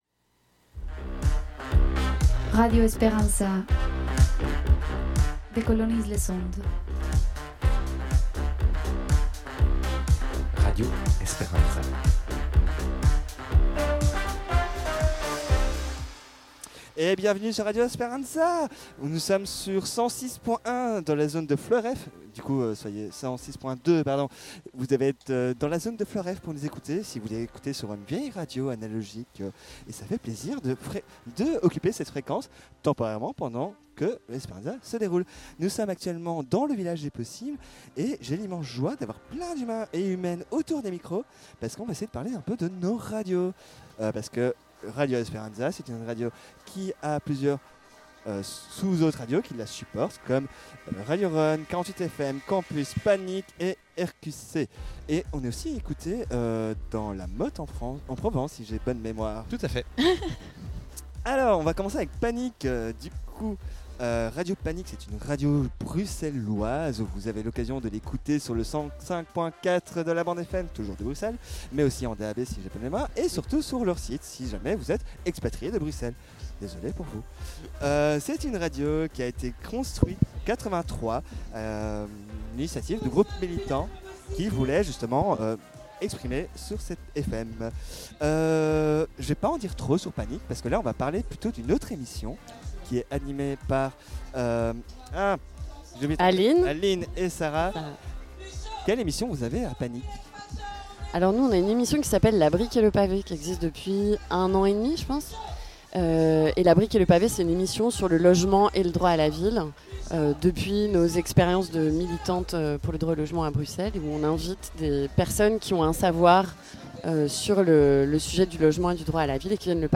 Discussion avec différentes radios associatives sur l'importance de leur existance et leur lutte contre l'extrême droite.